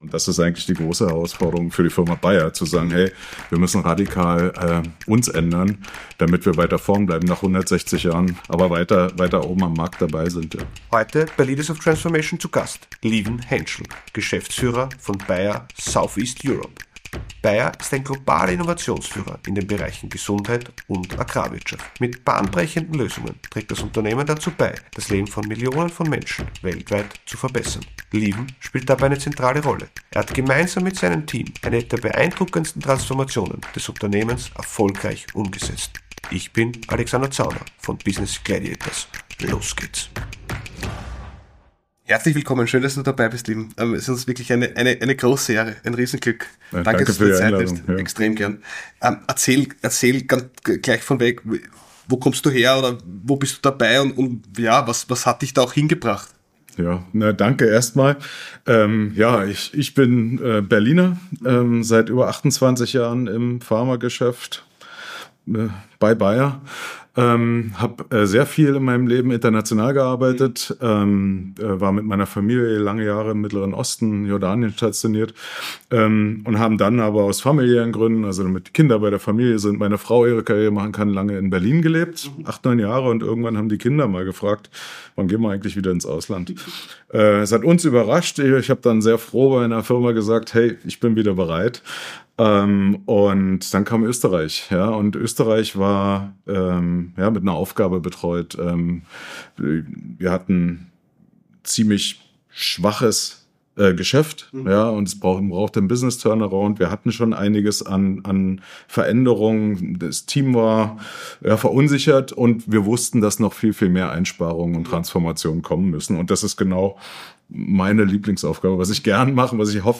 faszinierenden Gesprächen mit herausragenden Unternehmer:innen, Geschäftsführer:innen und Führungskräften